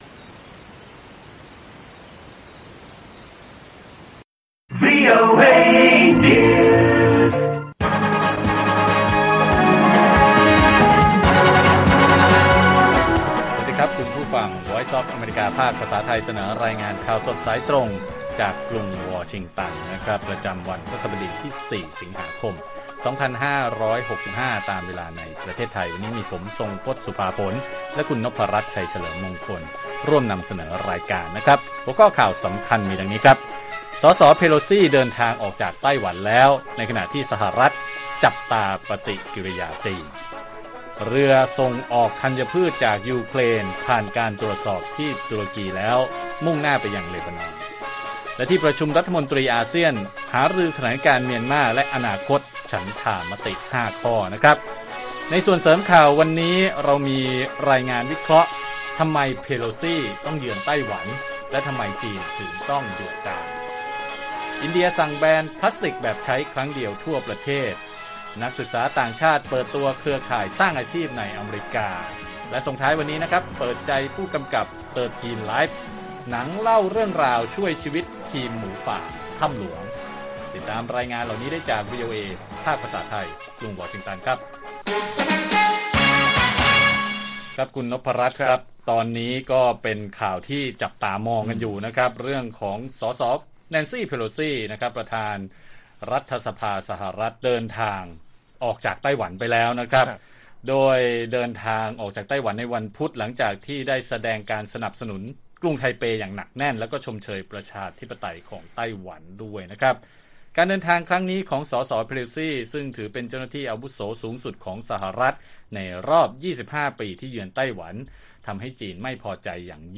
ข่าวสดสายตรงจากวีโอเอไทย 6:30 – 7:00 น. วันที่ 4 ส.ค. 65